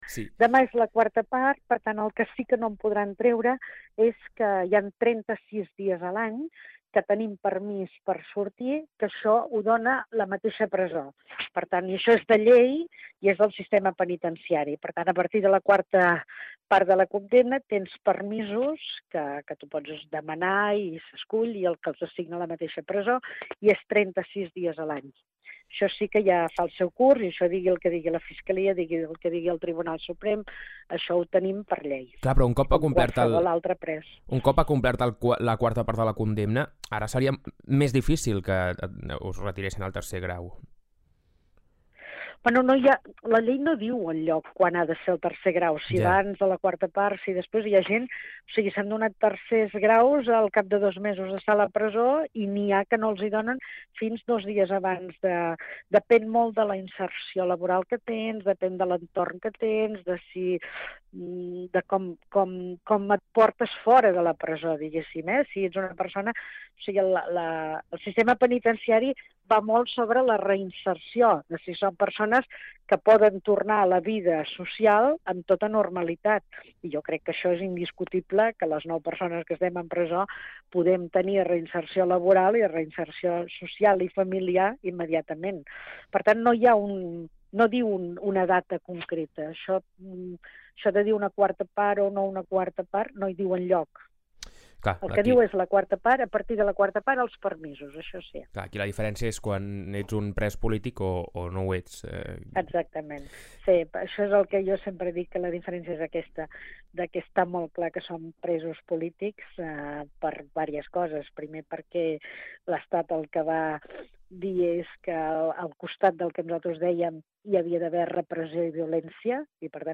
Entrevistes Supermatí
A l’entrevista del dimarts 9 de febrer vam parlar amb l’exconsellera Dolors Bassa, sobre com està passant el tercer grau i com encara la jornada electoral del 14 de Febrer.